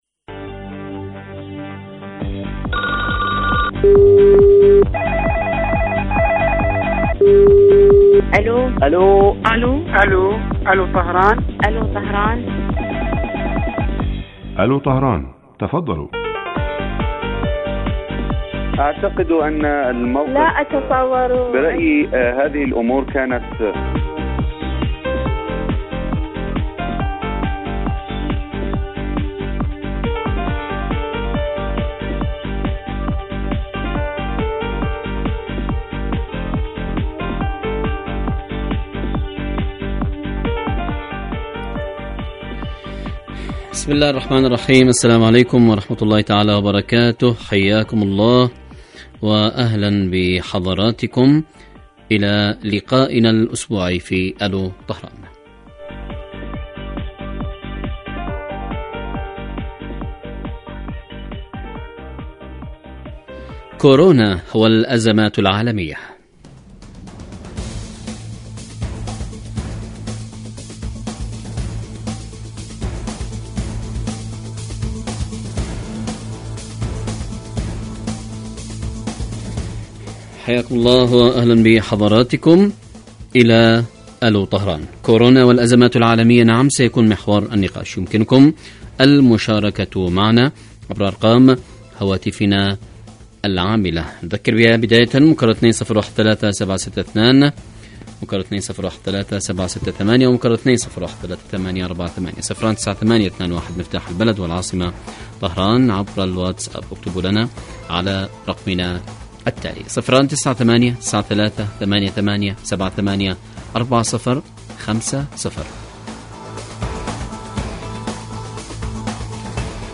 برنامج حي يهدف إلى تنمية الوعي السياسي من خلال طرح إحدى قضايا الساعة الإيرانية والعالمية حيث يتولى مقدم البرنامج دور خبير البرنامج أيضا ويستهل البرنامج بمقدمة يطرح من خلال محور الموضوع على المستمعين لمناقشته عبر مداخلاتهم الهاتفية .
يبث هذا البرنامج على الهواء مباشرة مساء أيام الجمعة وعلى مدى ثلاثين دقيقة